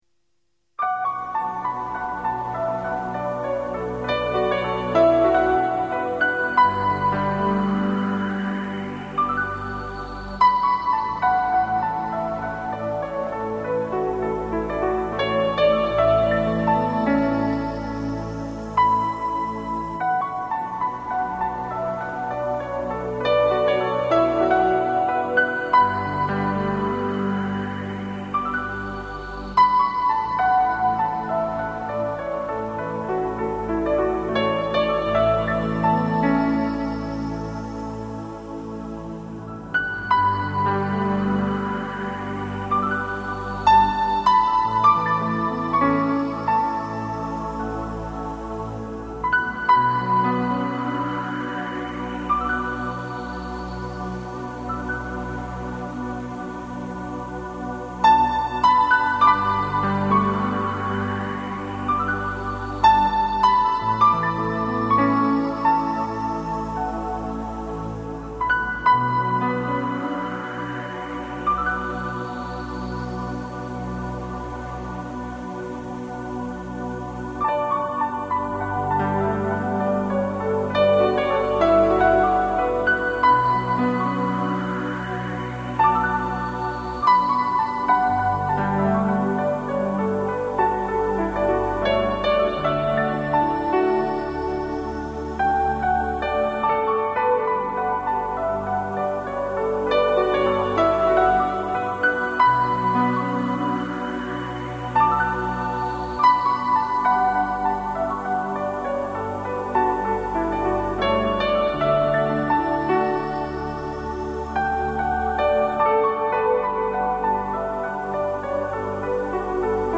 音乐评论家评说他的音乐犹如梦境般令人陶醉。其音乐风格以电子音乐为主，并且广为融合民族音乐的元素。